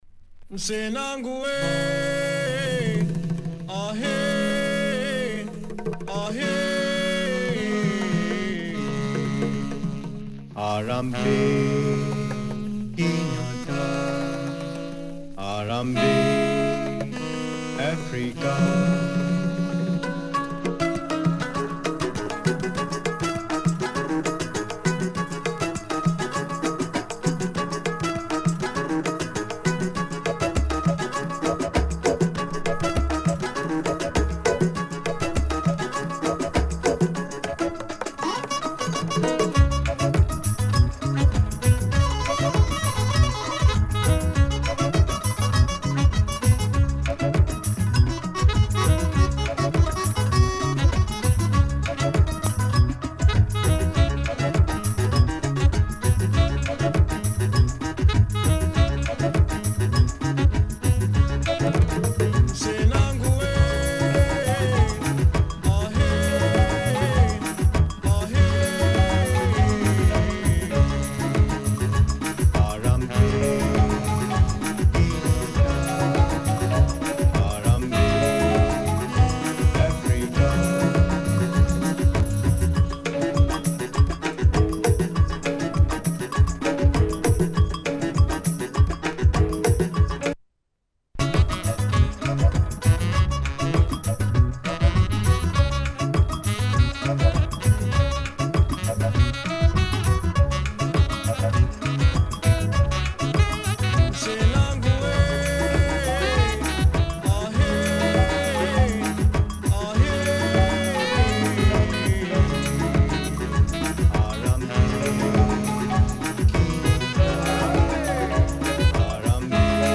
jazz rework
added bass and sax / percussion